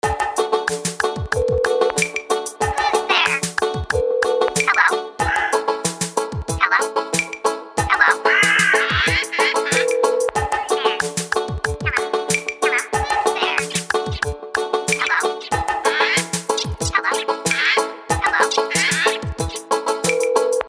Talking parrots
Free funny happy ringtone for your mobile phone